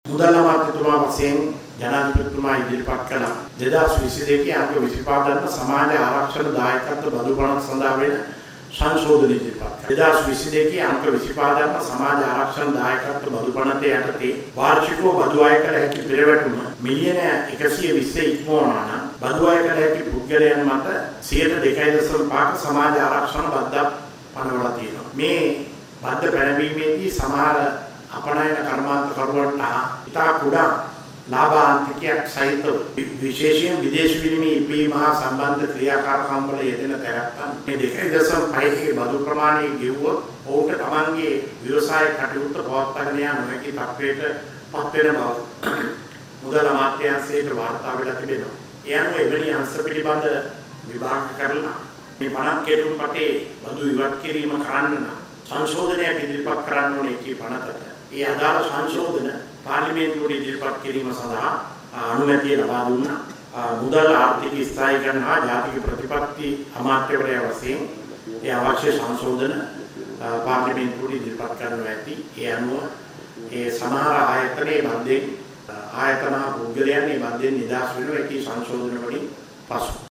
අද පැවති කැබිනට් තීරණ දැනුම් දීමේ මාධ්‍ය හමුවේදී කැබිනට් මාධ්‍ය ප්‍රකාශක බන්දුල ගුණවර්ධන මහතා මේ සම්බන්ධයෙන් අදහස් පල කළා.